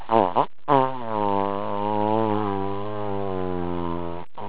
Swarm.wav